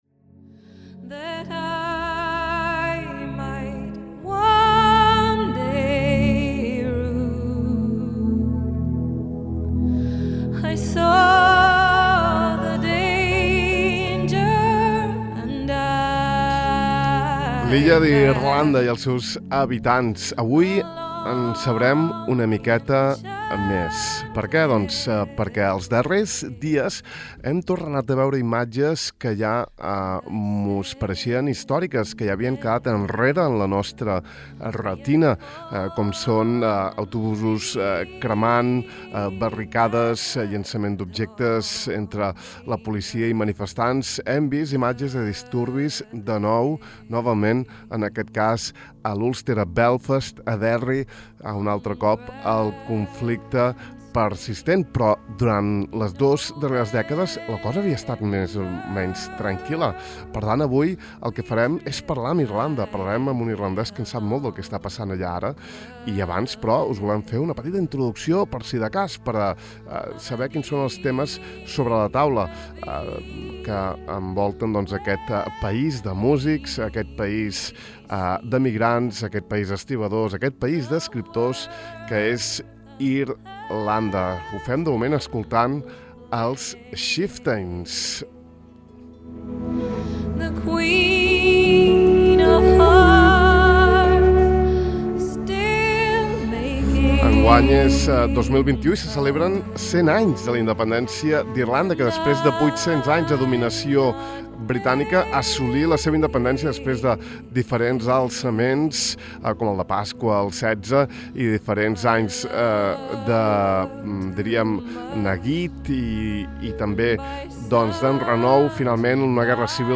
No us perdeu aquest especial de Ràdio Illa emès avui al De far a far: https